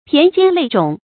駢肩累踵 注音： ㄆㄧㄢˊ ㄐㄧㄢ ㄌㄟˋ ㄓㄨㄙˇ 讀音讀法： 意思解釋： 同「駢肩累跡」。